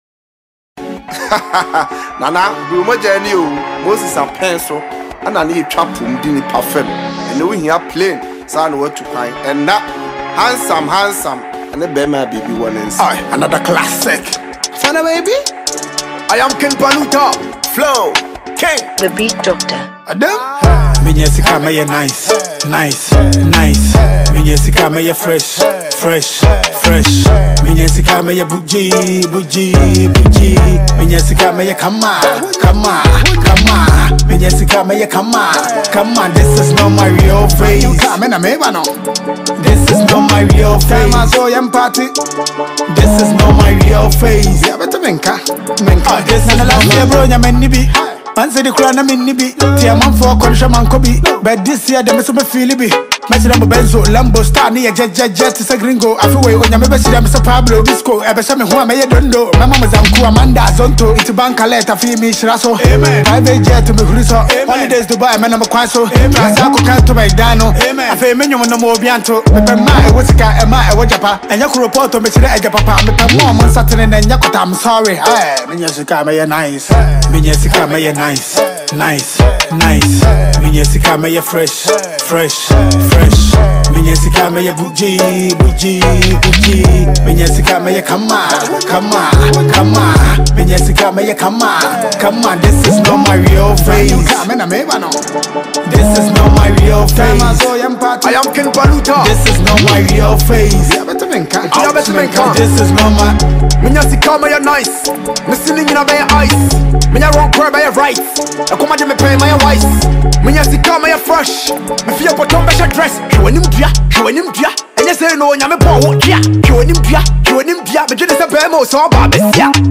Ghanaian rapper
street banger